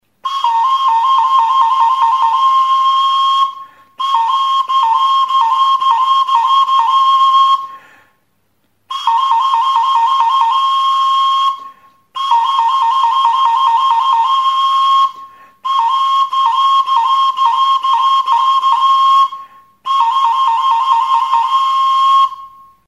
Music instrumentsSILBATO; FLAUTA
Aerophones -> Flutes -> Fipple flutes (one-handed)
Recorded with this music instrument.
Behekaldeko muturrean zulo txiki bat dauka txorien txioa imitatzeko.